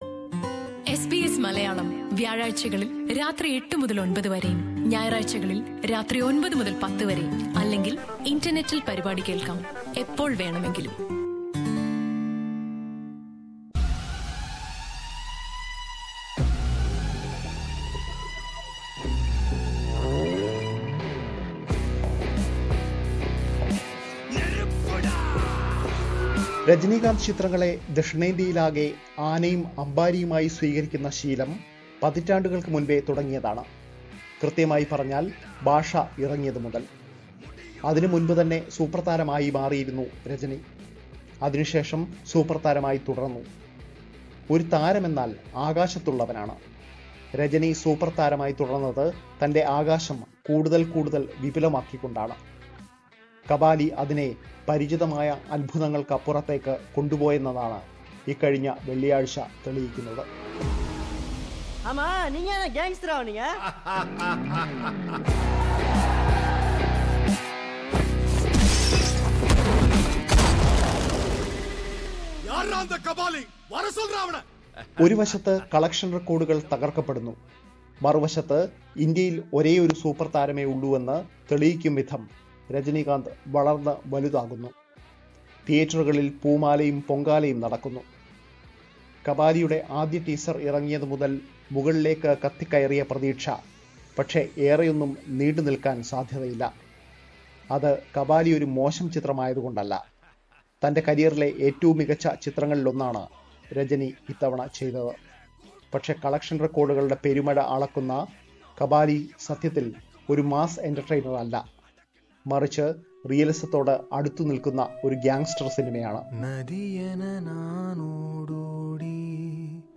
Review: Kabali